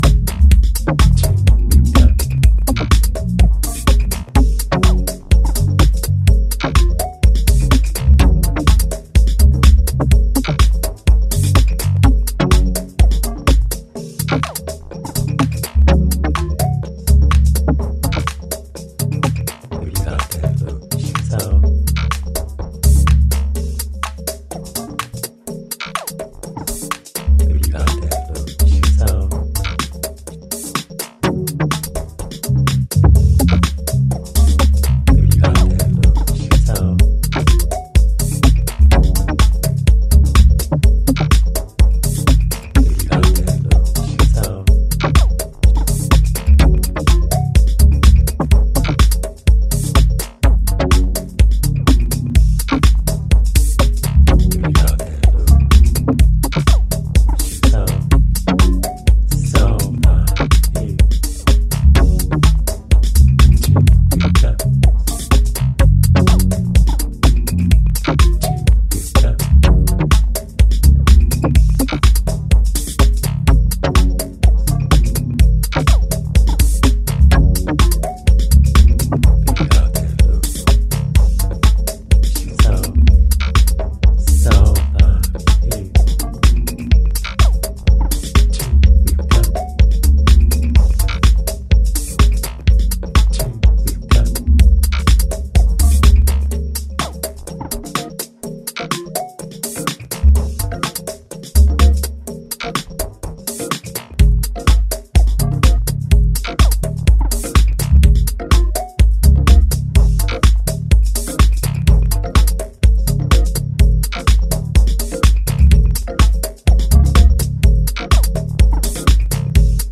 detail and timeless club music.